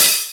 Index of /90_sSampleCDs/Best Service Dance Mega Drums/HIHAT HIP 1B